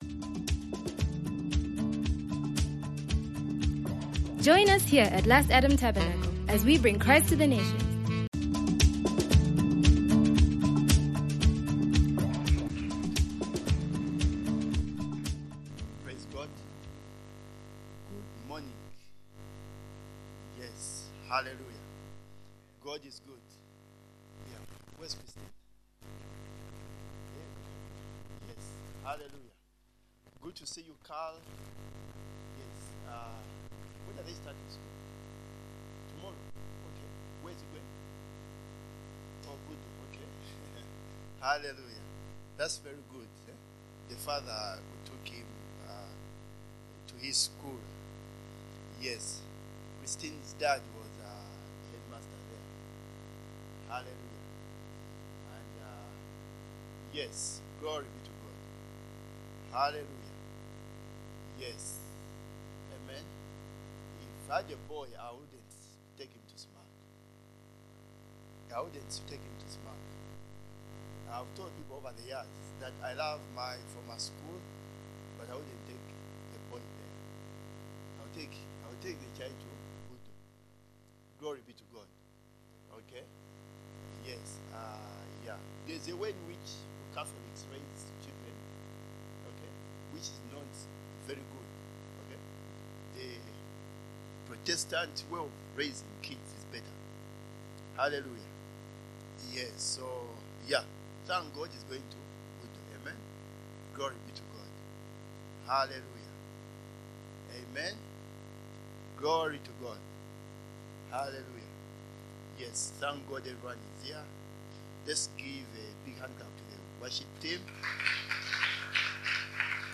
Latest Sermons